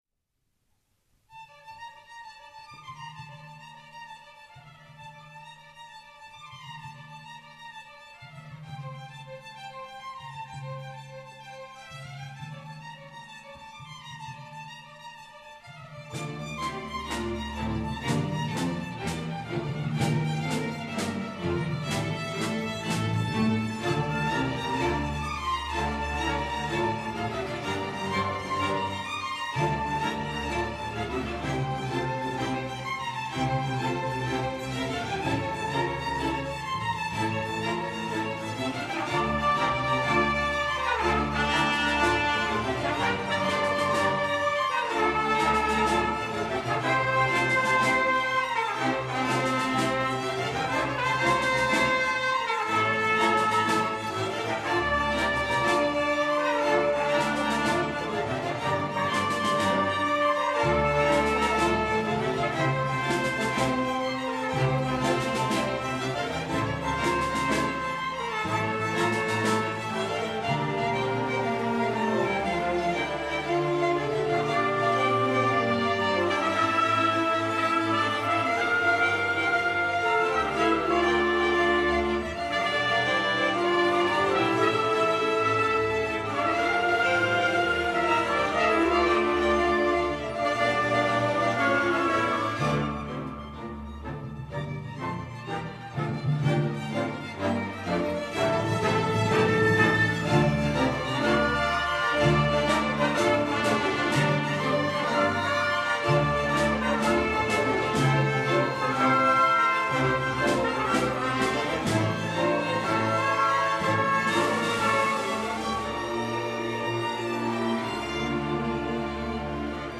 Dies ist die Ouvertüre zu einer 3-sätzigen Orchestersuite, die insgesamt gut 20 Minuten dauert.